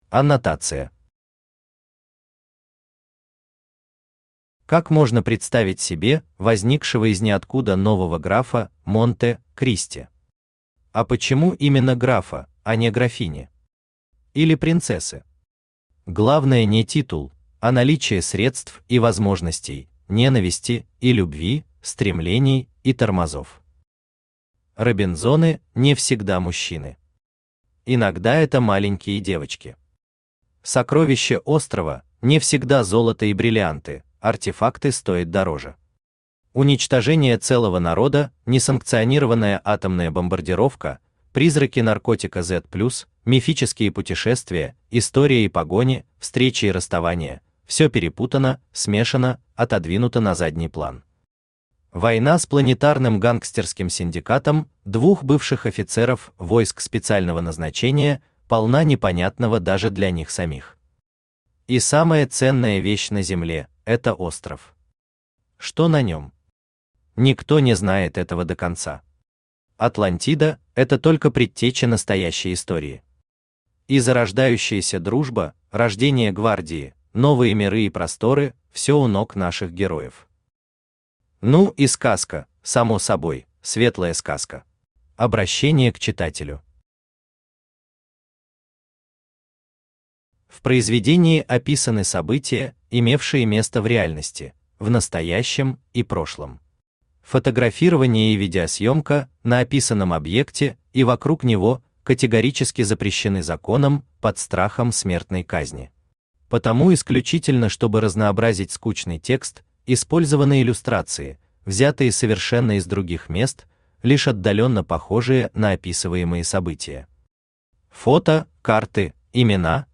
Aудиокнига Материализация легенды Автор Геннадий Анатольевич Бурлаков Читает аудиокнигу Авточтец ЛитРес.